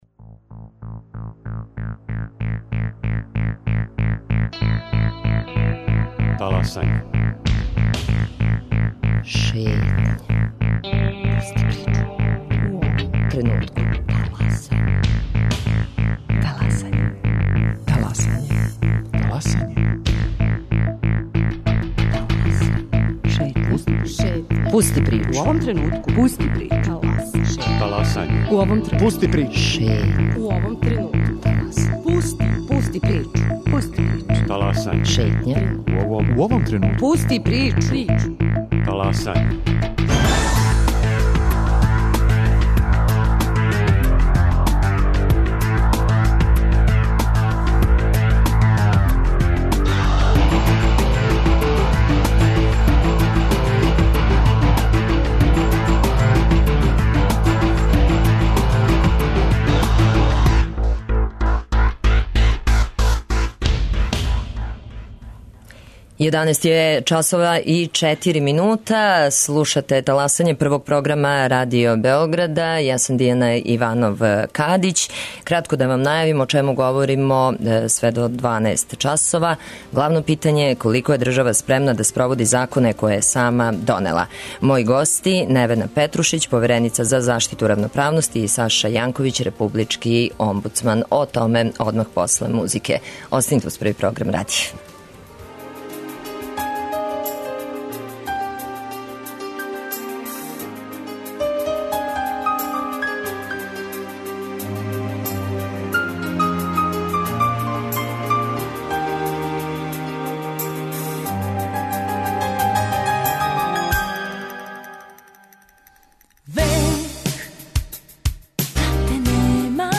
Гости: Невена Петрушић, повереница за заштиту равноправности и Саша Јанковић, републички омбудсман.